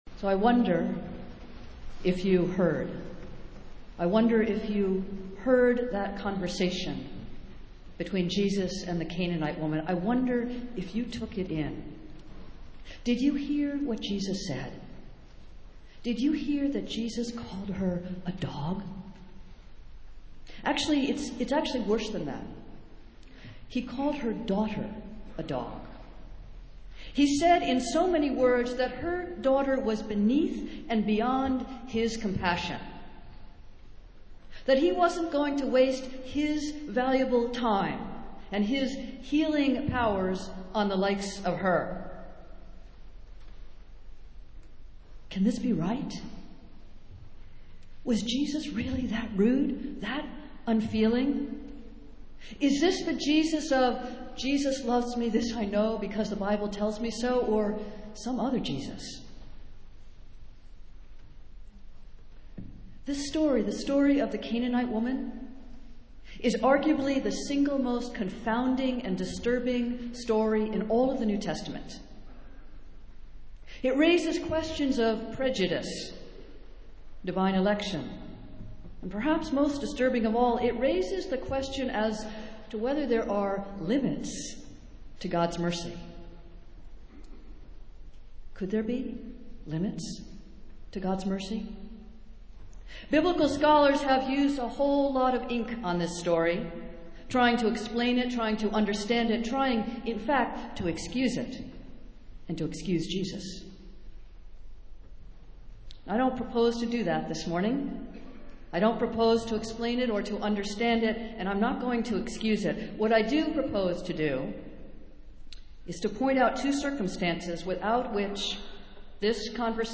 Festival Worship - Marathon Sunday